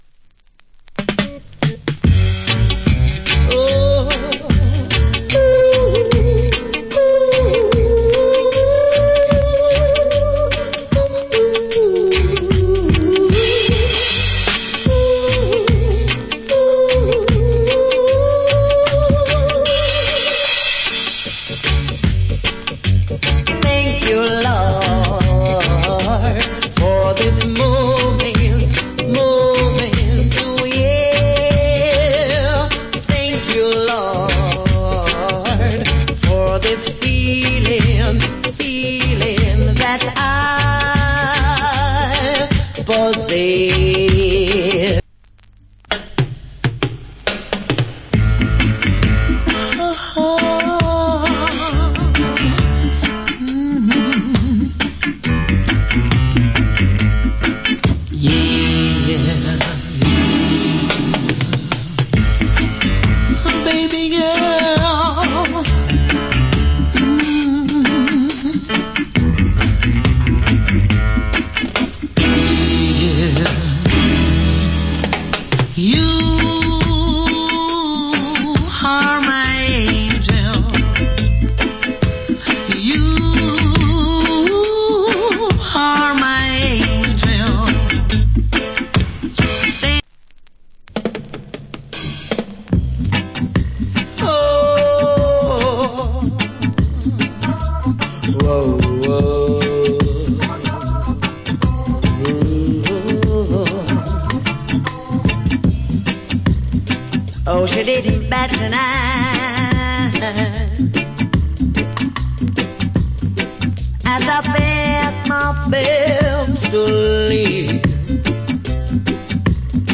Nice Roots & Reggae